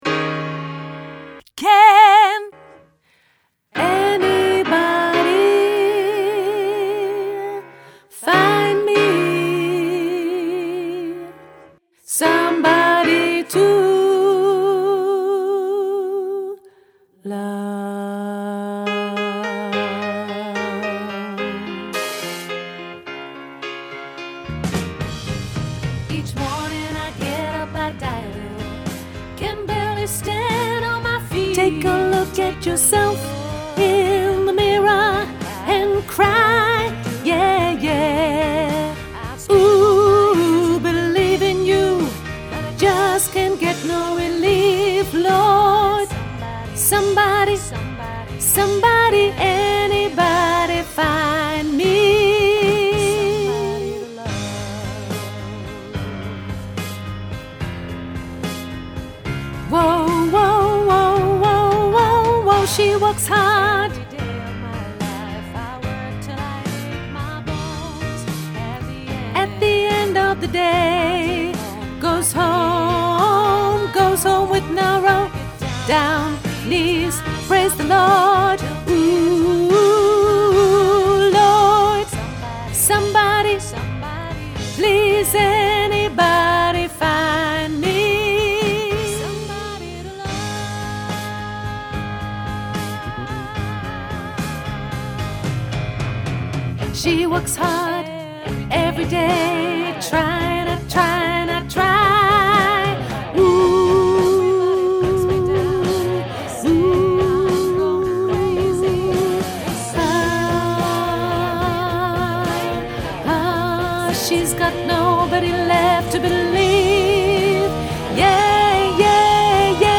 sopraan mezzo